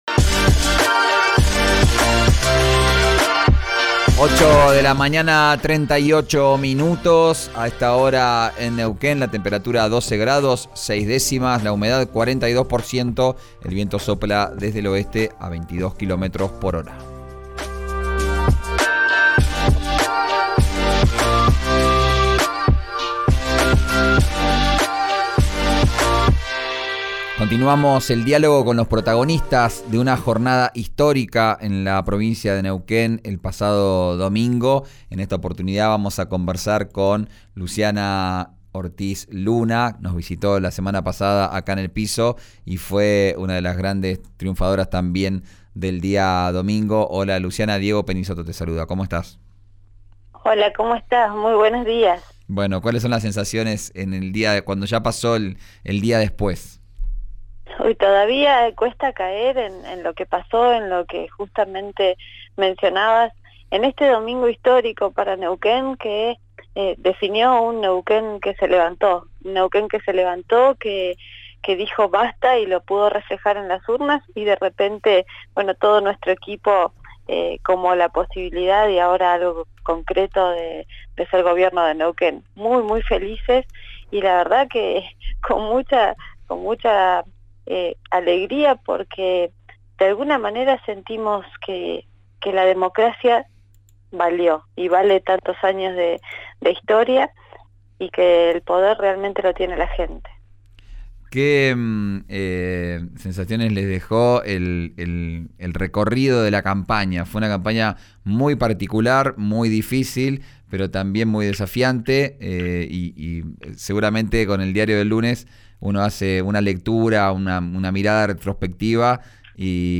La diputada provincial electa por el partido Comunidad de Rolando Figueroa, Luciana Ortiz Luna, en las elecciones del domingo habló en RIO NEGRO RADIO sobre los proyectos que presentará al asumir su banca en la Legislatura, pero no descartó ser parte del gabinete del futuro gobernador.